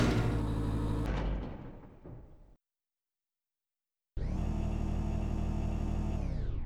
mechanical_door.wav